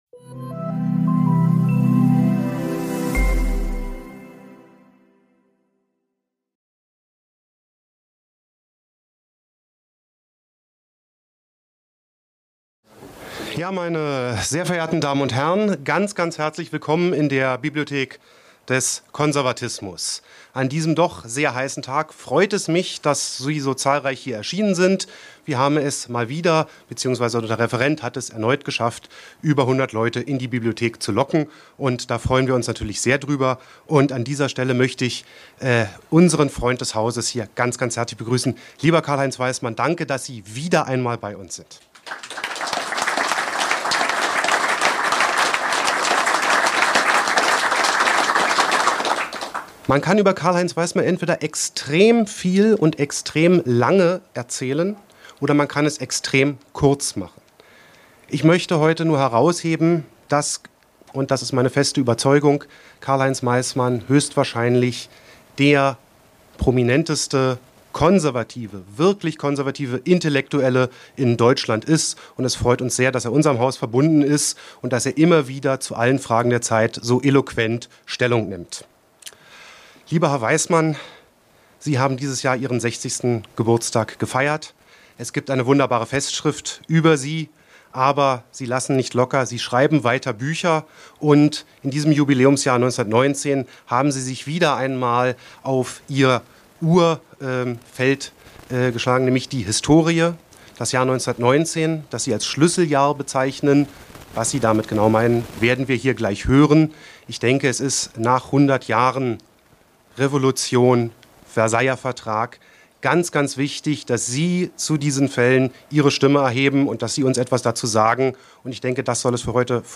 In seinem Vortrag vertrat der Göttinger Historiker die These, daß gerade 1919 ein Schicksalsjahr für die Deutschen gewesen sei.